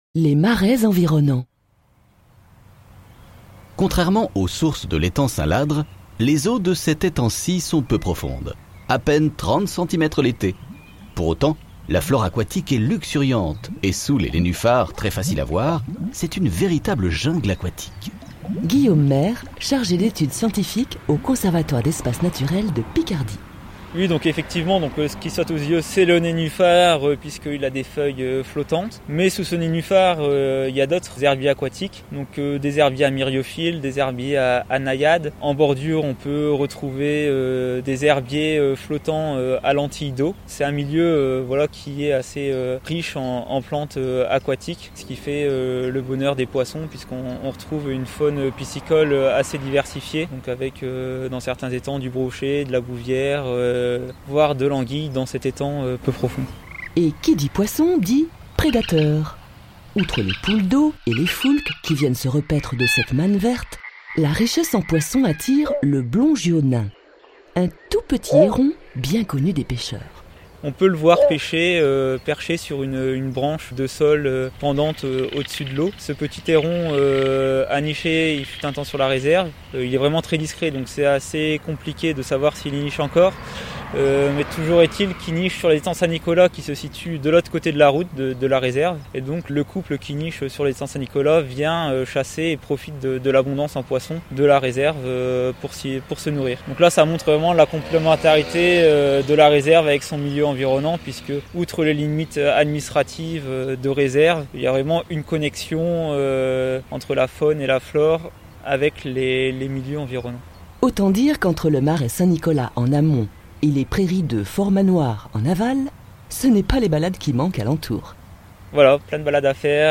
Un audioguide vous permet de visiter la réserve naturelle en compagnie d’un « guide virtuel ».